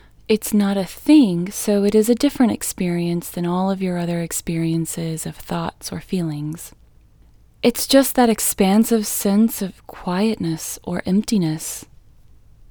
LOCATE OUT English Female 14